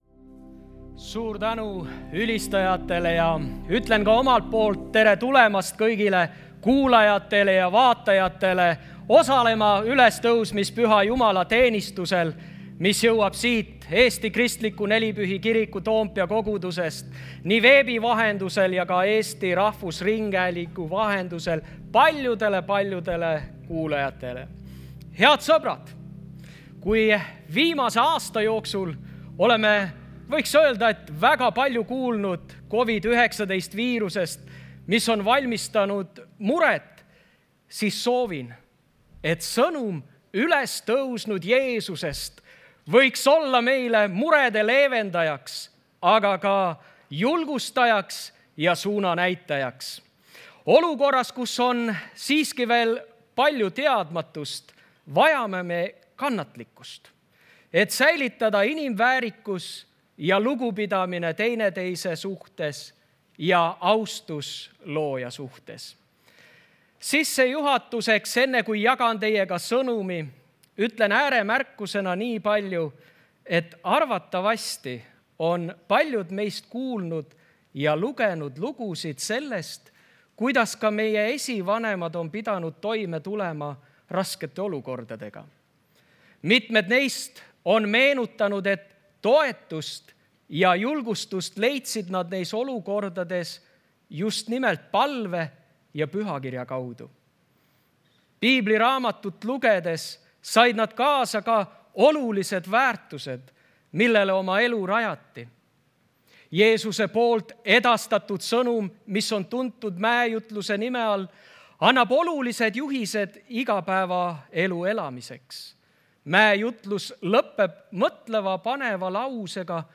Jutlused - EKNK Toompea kogudus
Kristlik ja kaasaegne kogudus Tallinna kesklinnas.